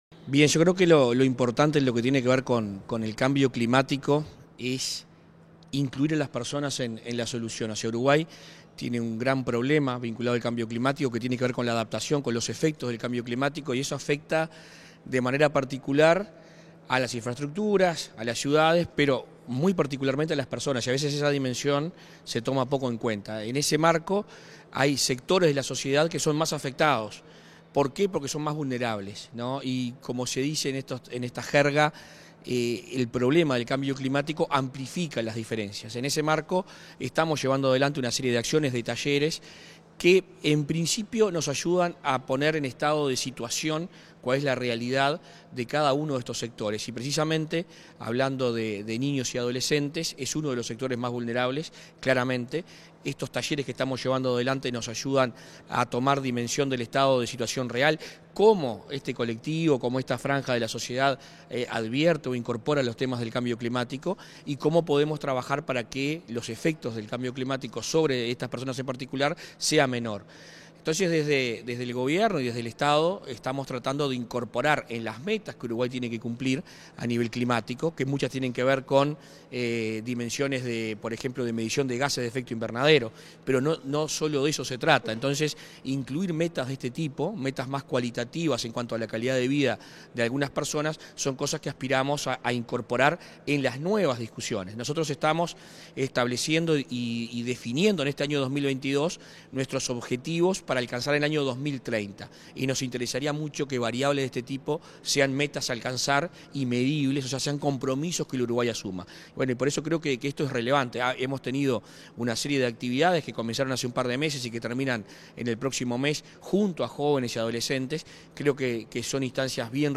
Entrevista al ministro de Ambiente, Adrián Peña
Entrevista al ministro de Ambiente, Adrián Peña 09/08/2022 Compartir Facebook Twitter Copiar enlace WhatsApp LinkedIn Tras participar en el seminario Perspectiva de Infancia y Adolescencia en las Políticas de Cambio Climático y Ambiente, este 9 de agosto, el ministro de Ambiente efectuó declaraciones a Comunicación Presidencial.